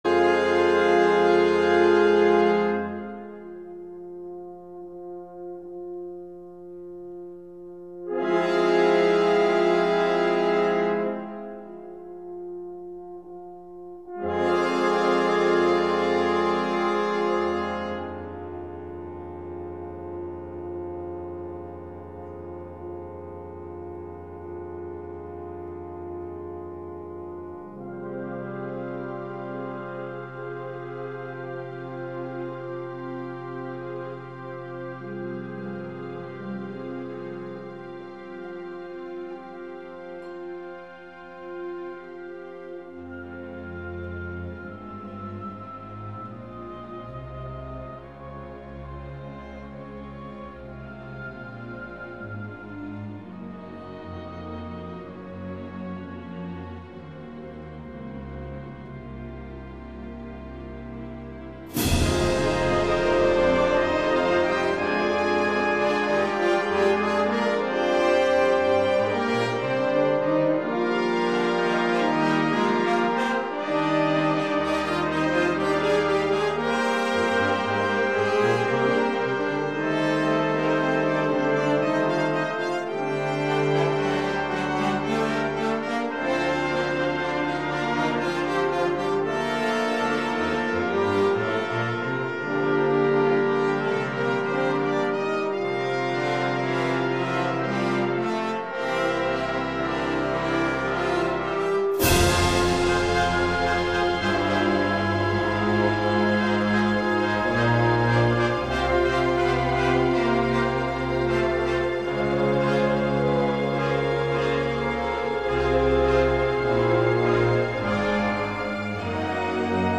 Música académica